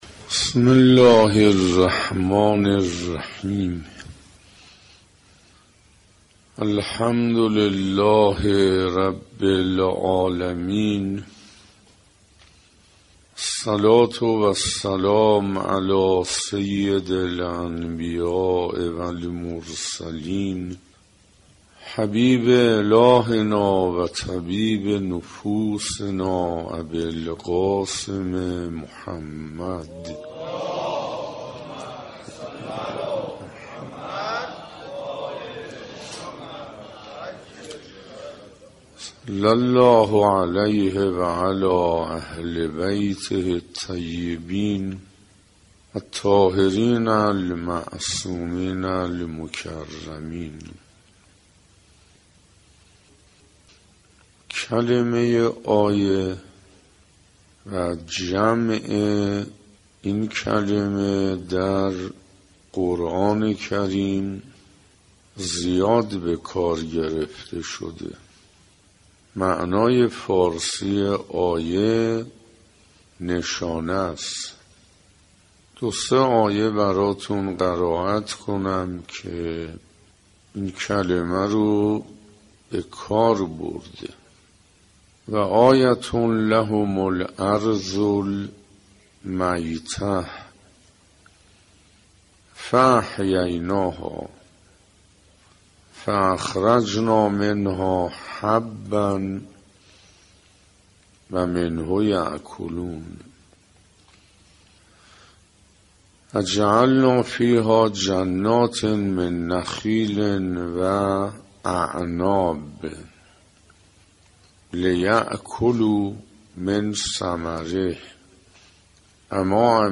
دانلود نوزدهمین جلسه از بیانات آیت الله حسین انصاریان با عنوان «ارزشهای ماه رمضان»